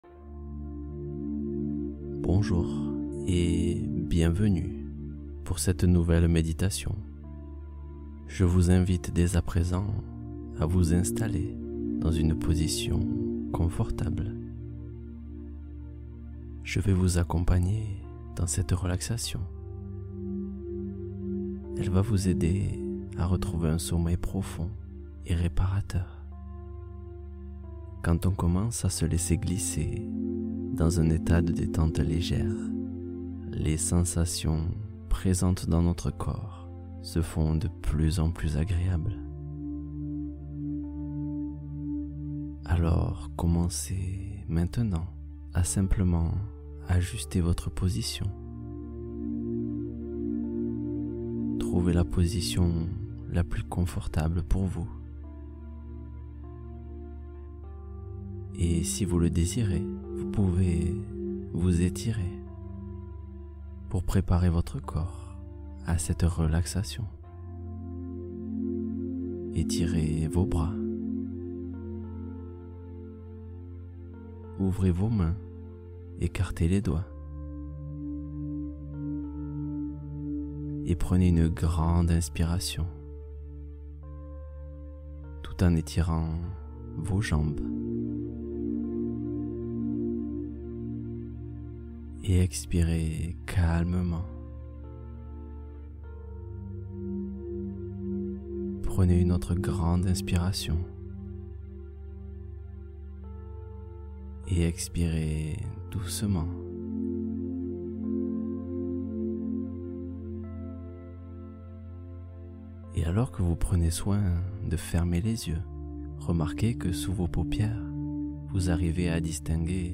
Confiance profonde — Méditation guidée d’introspection